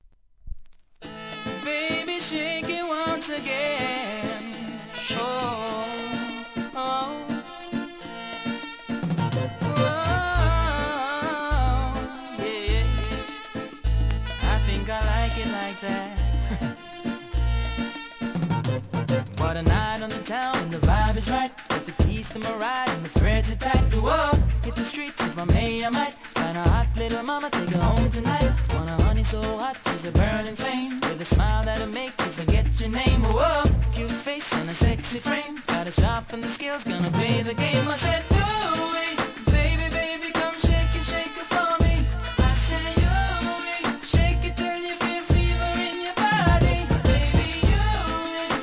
REGGAE
哀愁上物が印象的な2003年ヒットRiddim!!